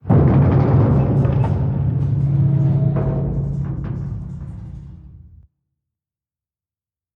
StructureCrunch4.ogg